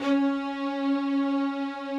strings_049.wav